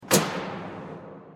-Light on and off
heavylightswitch.ogg